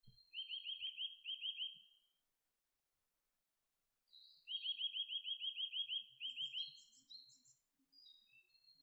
502gojukara_saezuri.mp3